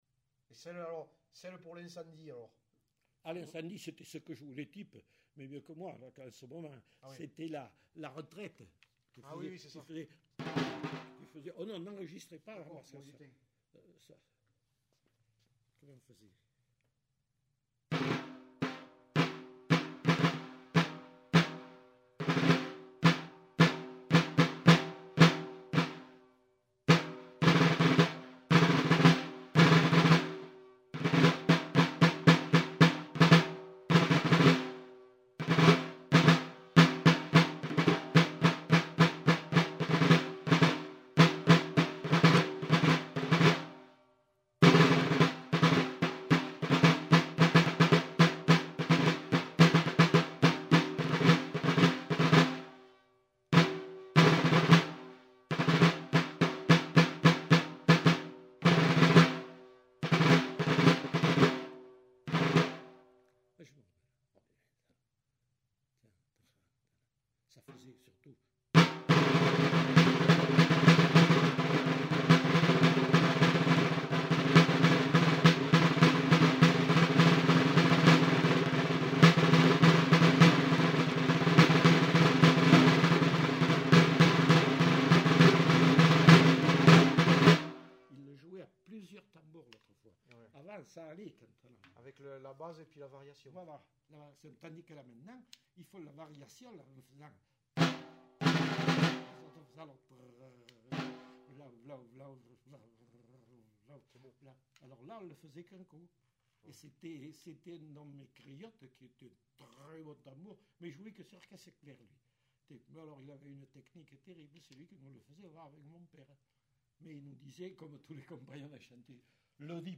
Aire culturelle : Quercy
Genre : morceau instrumental
Descripteurs : musicien municipal
Instrument de musique : tambour